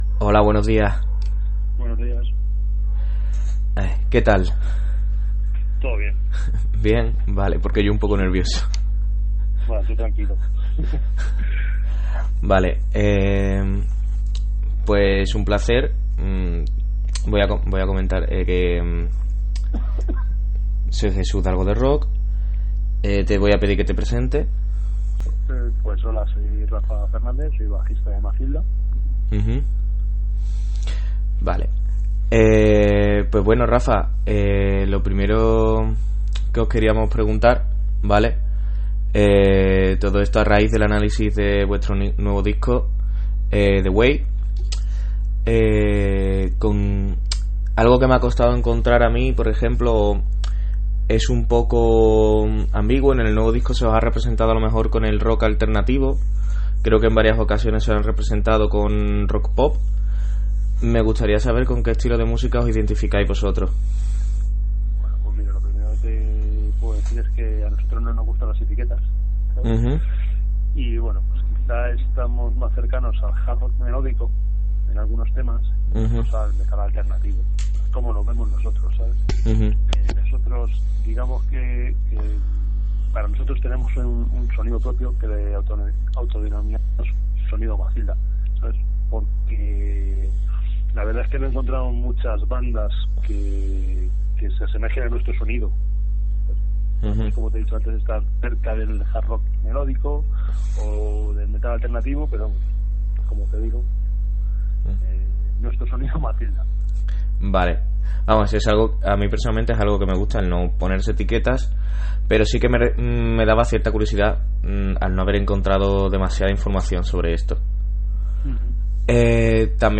[Audio-Entrevista] con MATHILDA